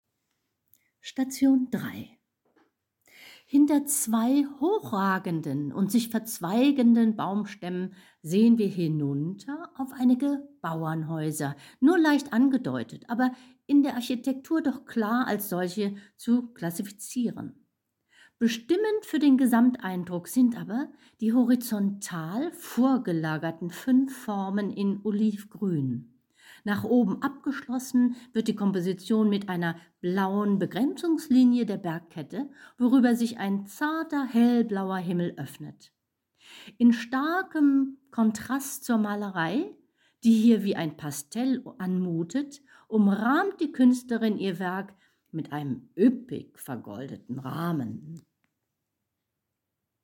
Führung durch Mack's-Kunstdepot 2023